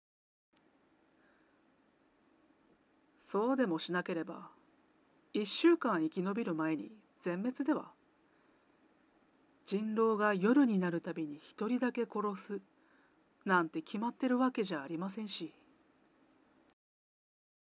夜凪 俊)セリフ2 冷たく nanaRepeat